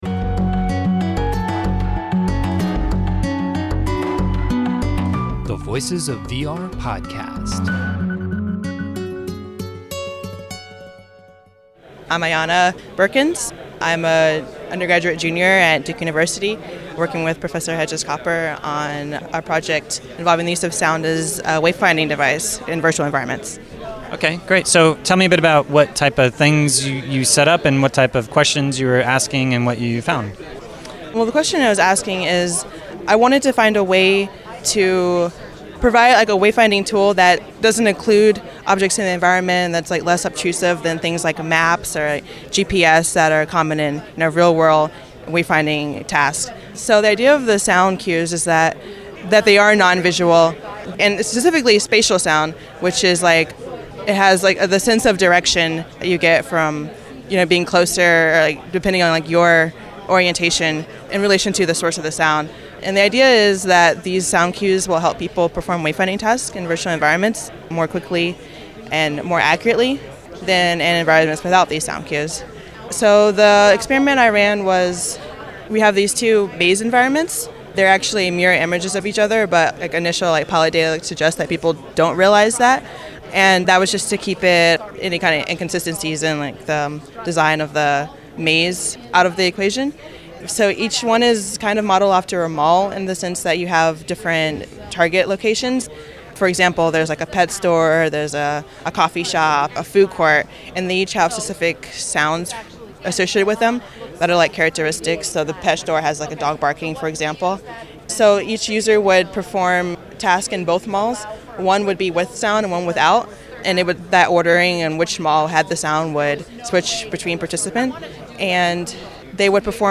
Theme music: “Fatality” by Tigoolio